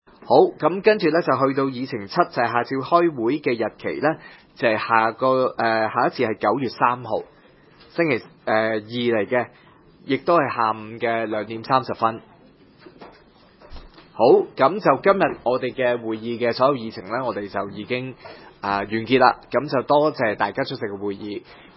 區議會大會的錄音記錄
黃大仙區議會會議室